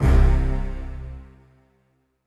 STR HIT C1 2.wav